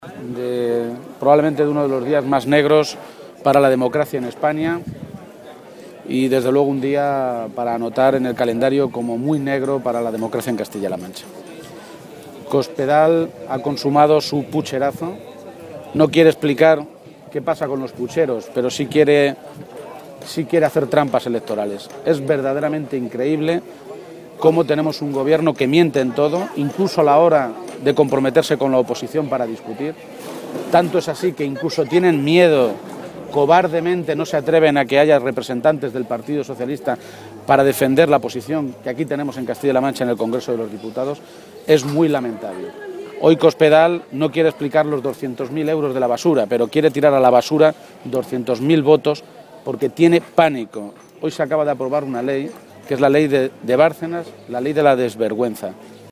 Emiliano García-Page, Secretario General del PSOE de Castilla-La Mancha, frente al Palacio de Fuensalida
Cortes de audio de la rueda de prensa